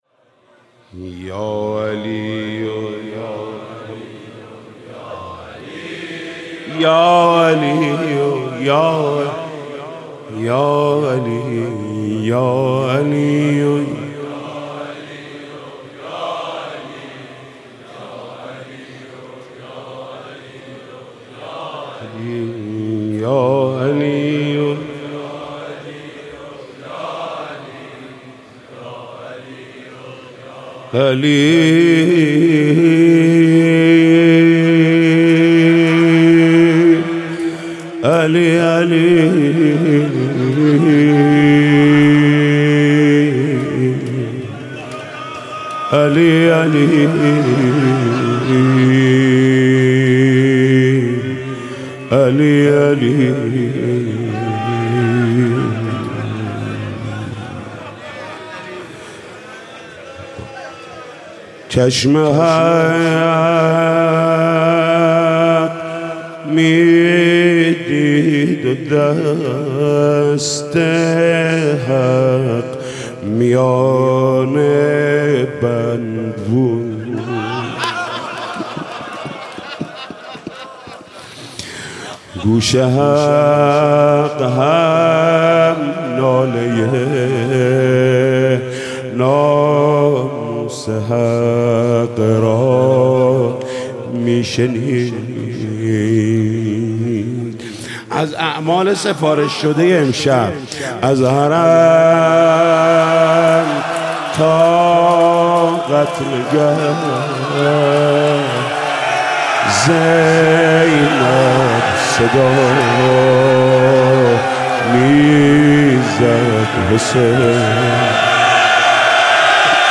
«شب نوزدهم» مناجات: یه رعیت امشب اومده